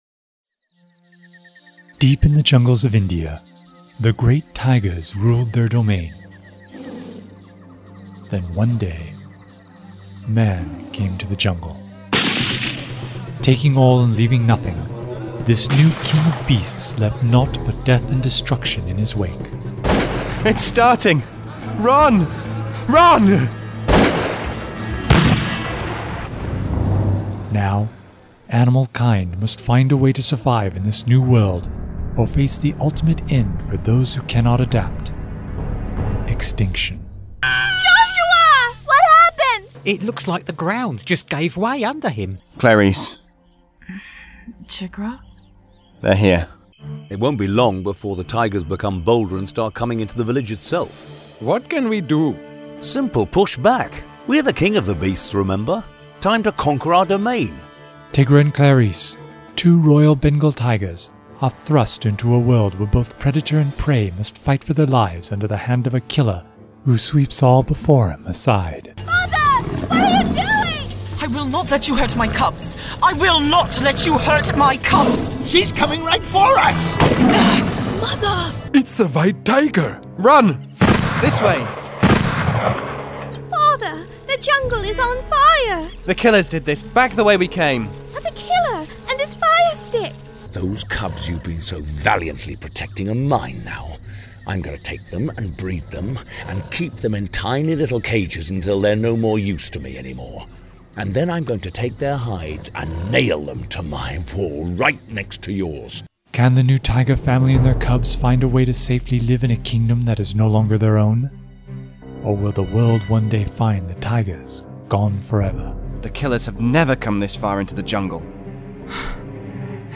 An original audio drama from Everlasting Films
Trailer